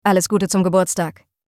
Listen to the correct pronunciation here: